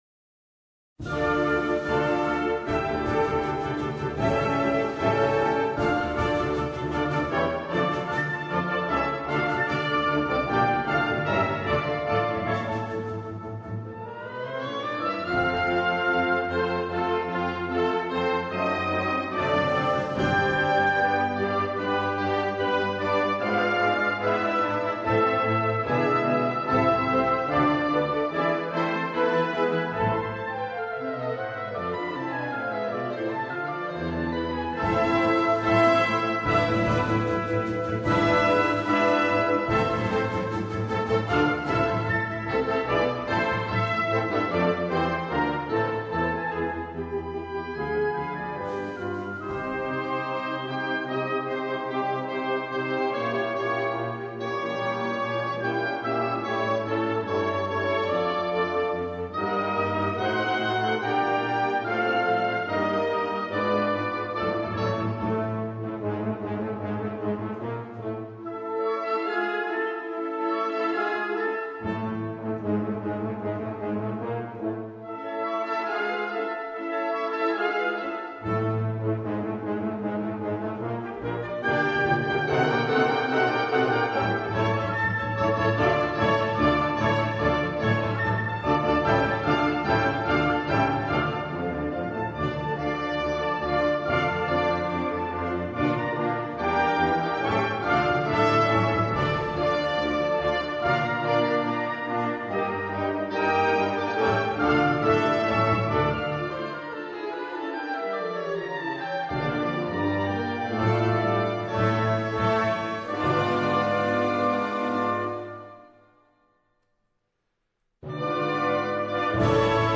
per coro ad lib. e banda